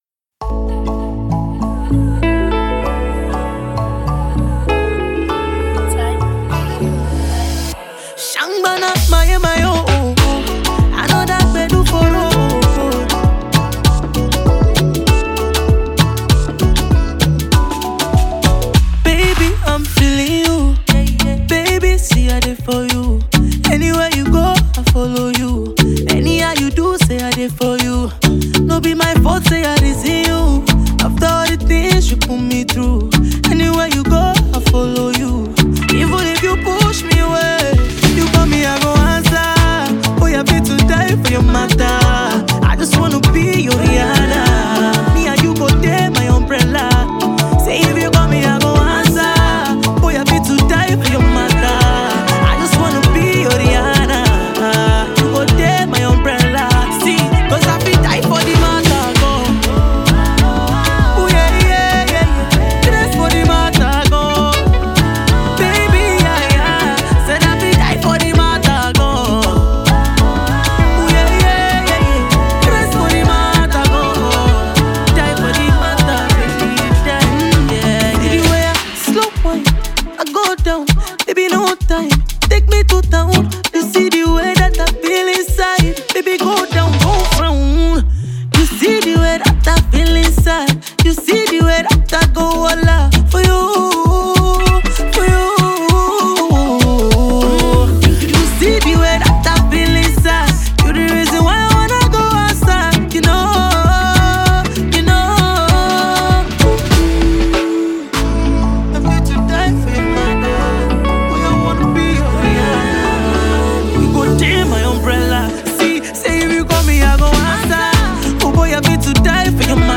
Nigerian silky voice singer and songwriter